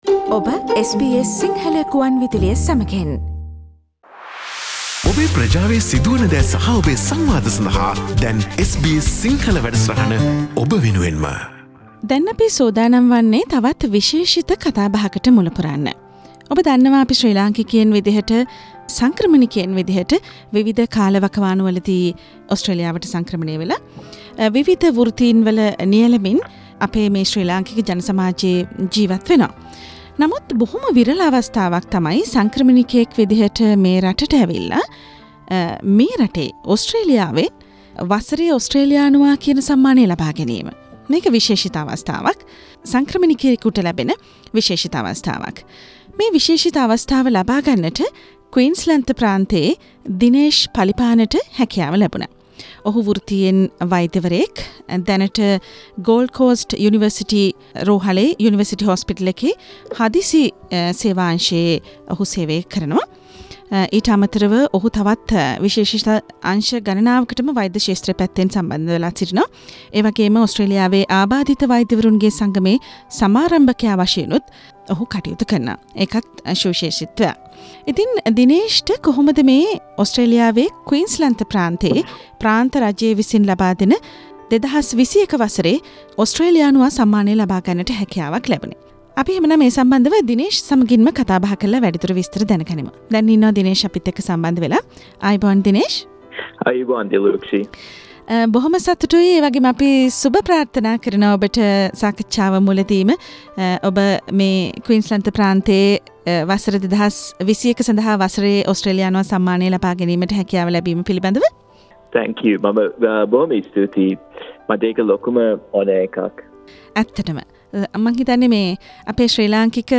සාකච්චාව